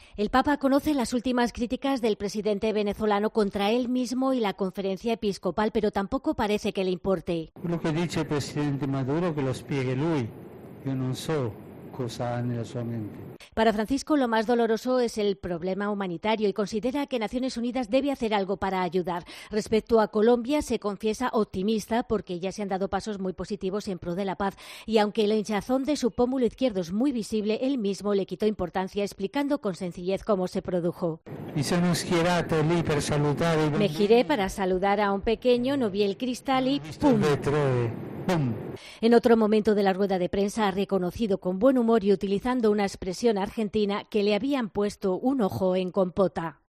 El Papa habla de Venezuela, Colombia y Estados Unidos en el avión. Crónica